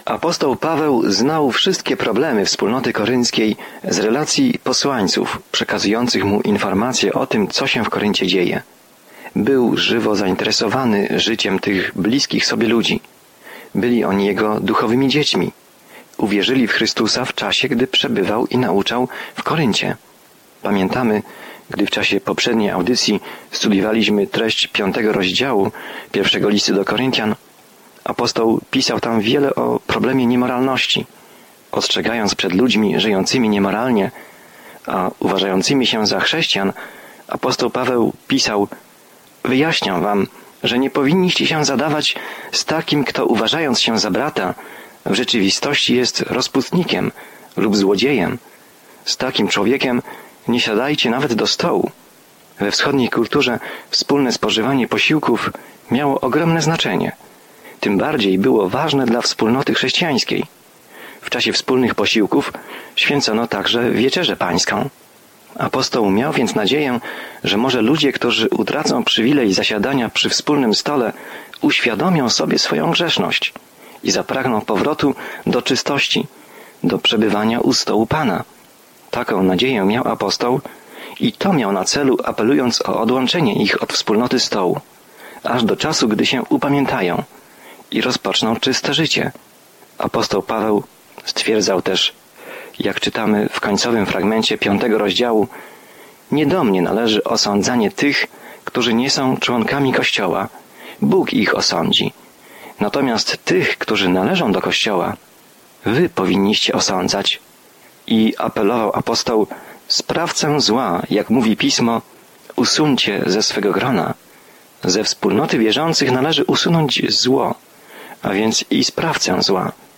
Jest to temat poruszony w Pierwszym Liście do Koryntian, zawierający praktyczną opiekę i korektę problemów, przed którymi stają młodzi chrześcijanie. Codziennie podróżuj przez 1 List do Koryntian, słuchając studium audio i czytając wybrane wersety słowa Bożego.